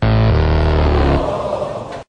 fail.ogg